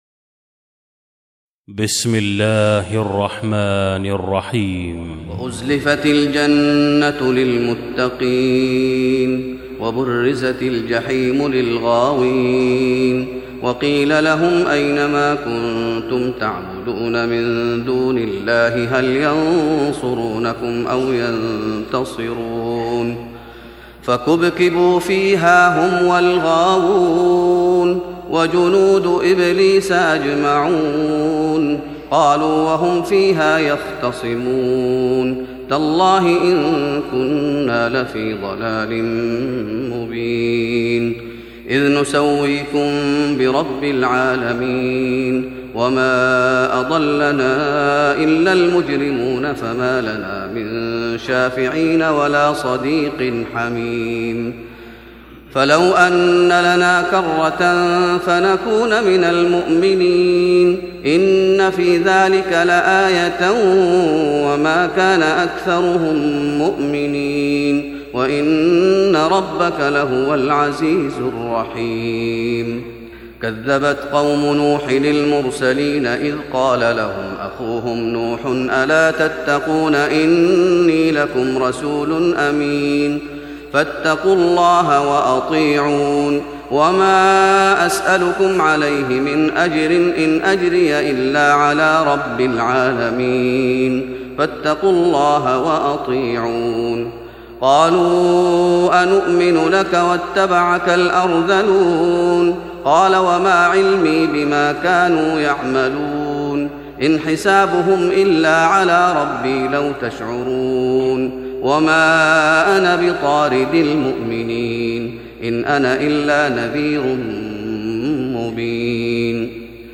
تراويح رمضان 1415هـ من سورة الشعراء (90-227) Taraweeh Ramadan 1415H from Surah Ash-Shu'araa > تراويح الشيخ محمد أيوب بالنبوي 1415 🕌 > التراويح - تلاوات الحرمين